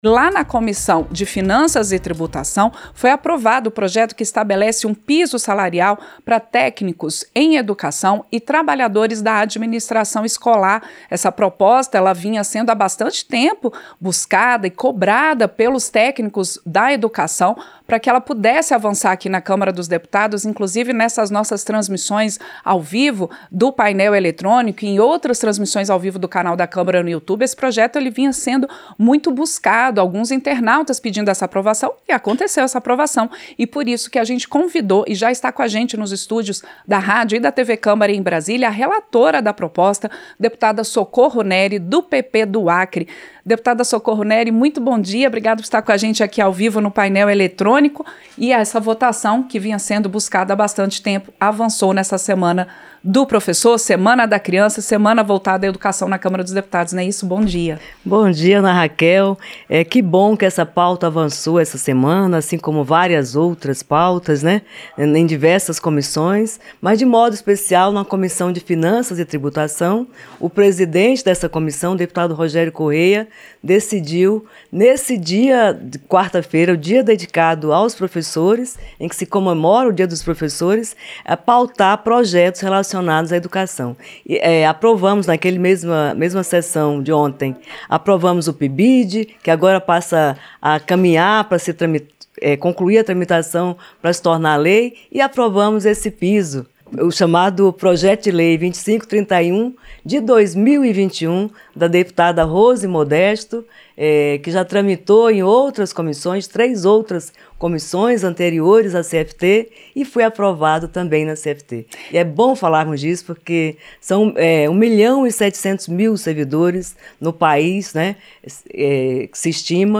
Entrevista - Dep. Socorro Neri (PP-AC)